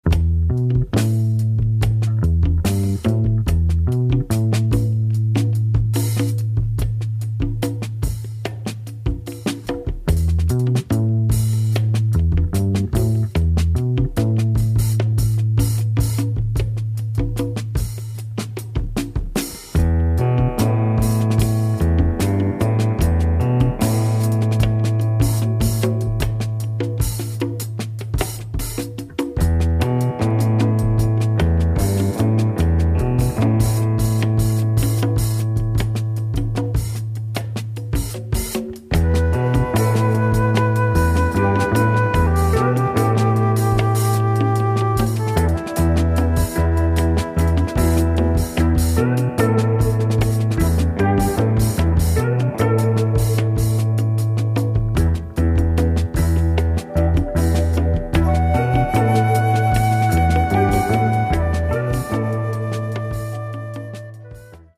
Great groovy drama sound and psychedelic jazz !